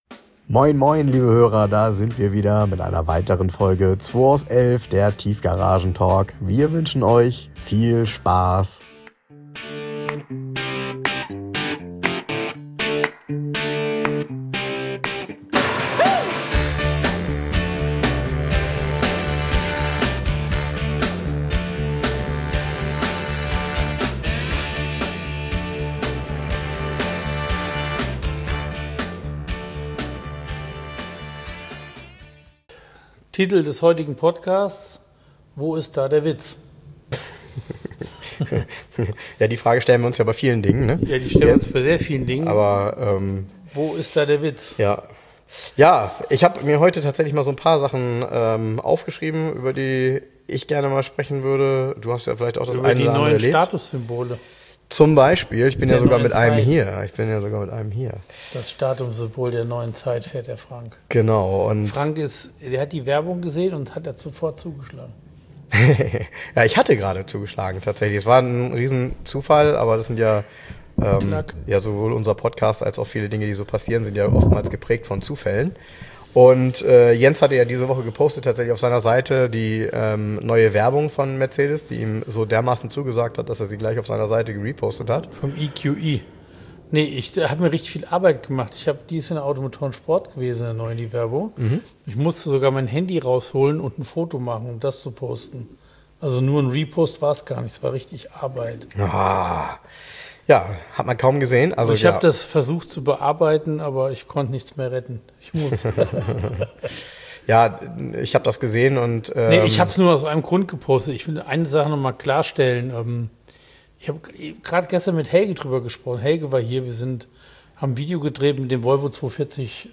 Diesen Podcast haben wir live in der Oldtimer Tankstelle aufgenommen.